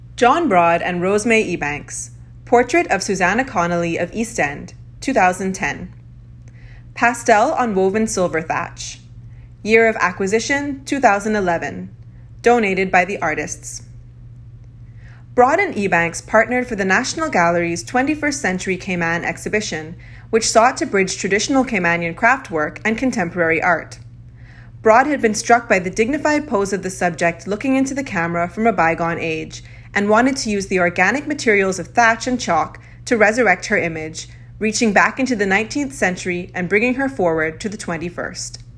(English) John Broad and Rose May Ebanks - Portrait of Susanna Connolly of East End Voiceover (Español) John Broad y Rose May Ebanks - Retrato de Susanna Connolly de East End Narración